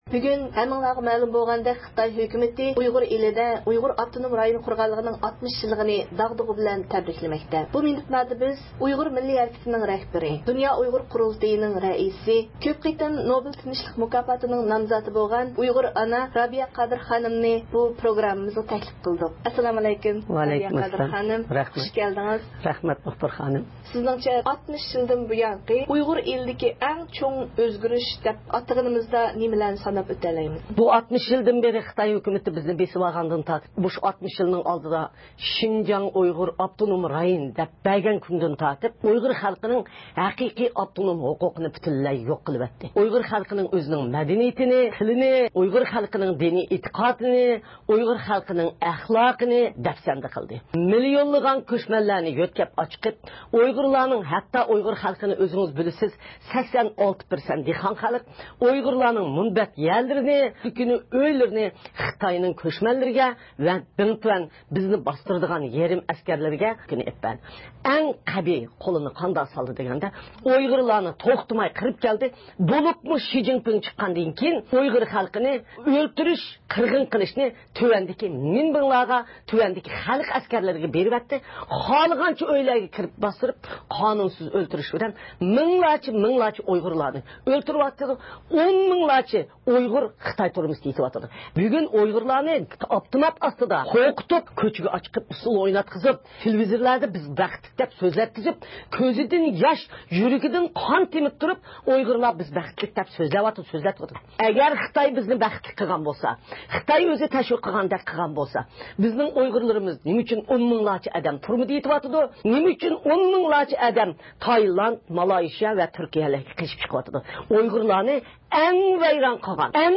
يۇقىرىدىكى ئۇلىنىشتىن مۇخبىرىمىزنىڭ رابىيە قادىر خانىم بىلەن ئېلىپ بارغان سۆھبىتىنىڭ تەپسىلاتىنى ئاڭلايسىلەر.